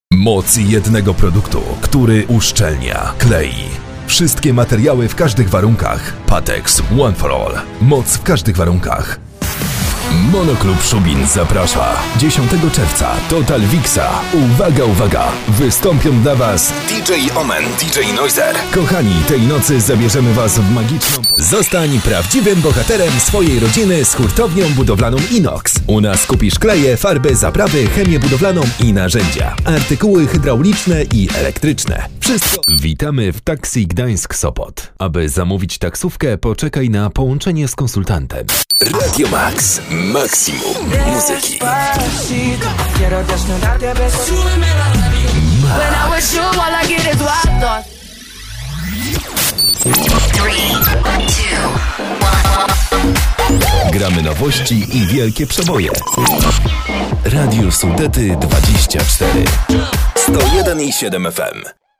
Male 20-30 lat
A voice for dynamic productions.
Demo lektorskie